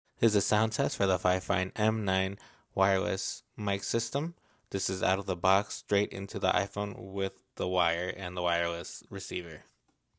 Wherever you end up recording, the M9 system boasts a clear and crisp capture at a magnificent price point.
Sound Test